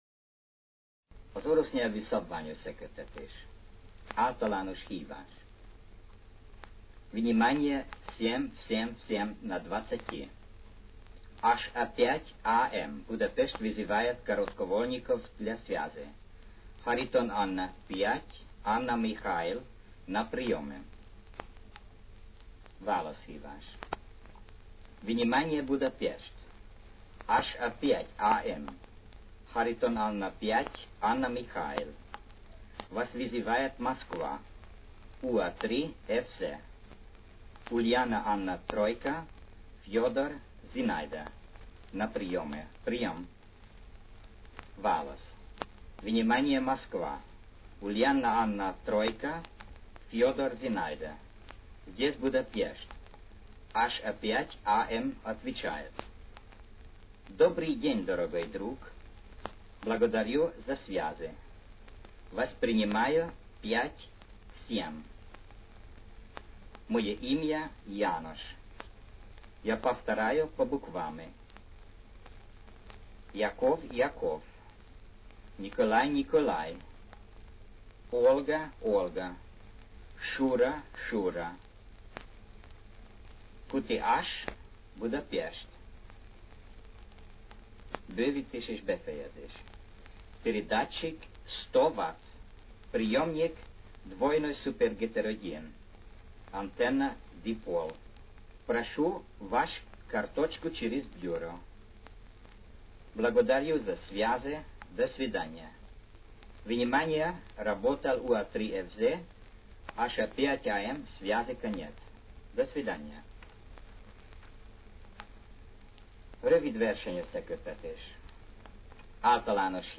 Meghallgatható, egy-egy minta forgalmazás, morzejelekkel, és távbeszélõ üzemmódban.